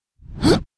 swordman_attack7.wav